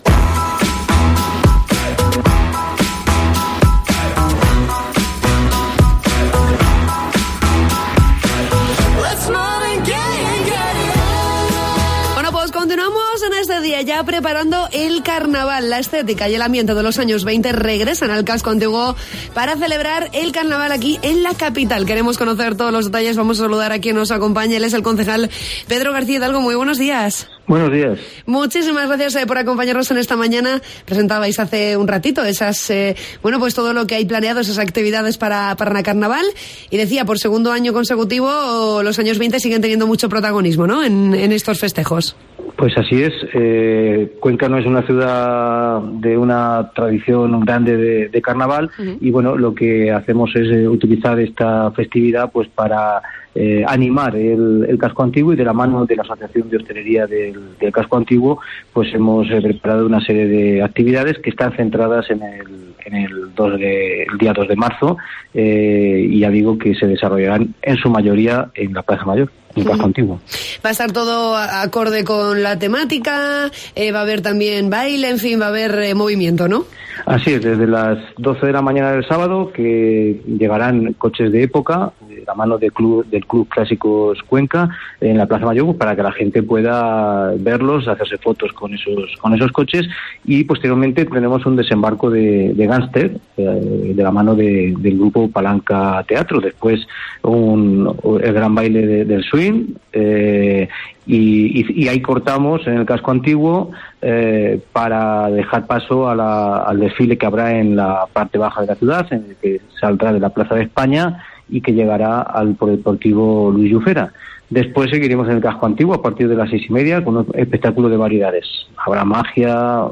Entrevista con el concejal de Festejos, Pedro García Hidalgo